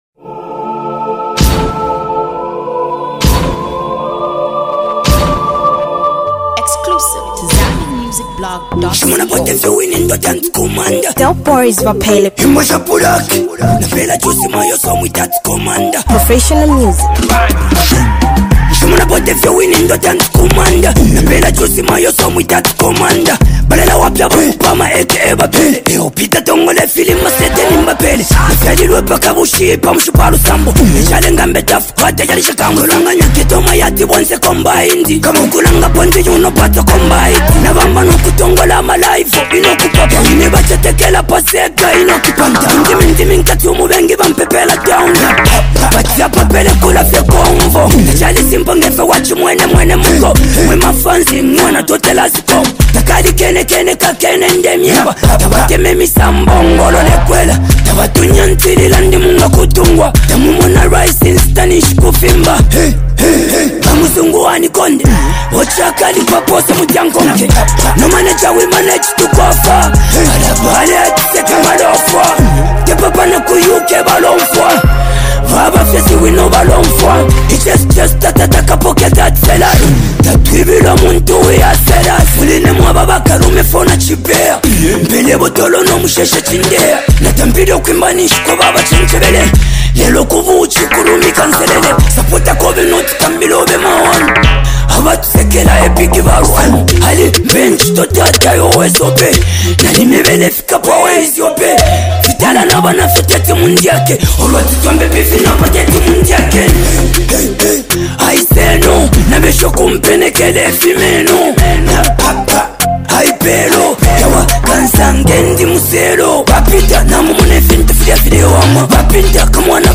Zambian rap
a high-energy lyrical session
Backed by a gritty, bass-heavy instrumental